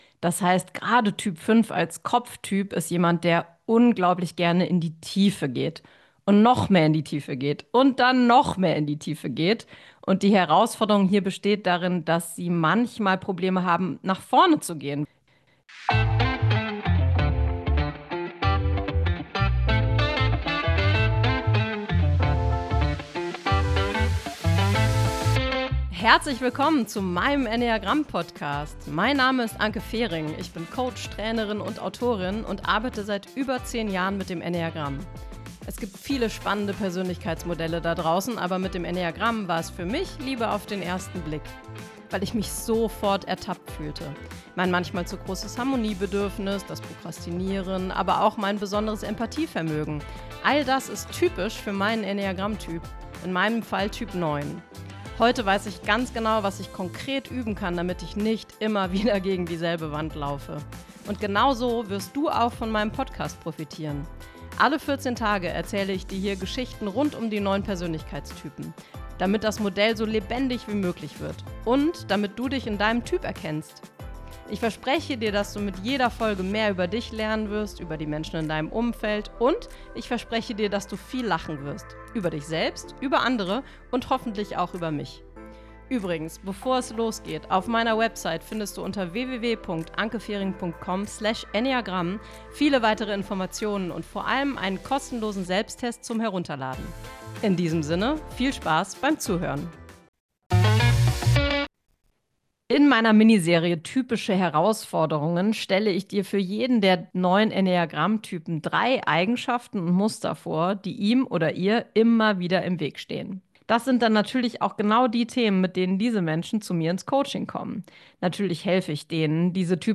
Solofolge_Herausforderungen_Typ5.mp3